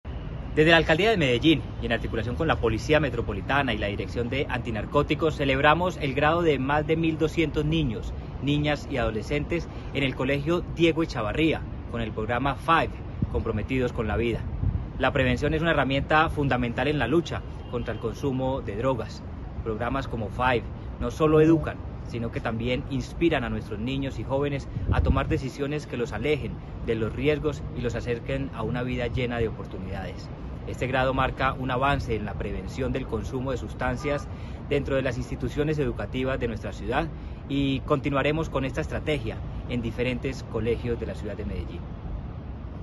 Palabras de Manuel Villa Mejía, secretario de Seguridad y Convivencia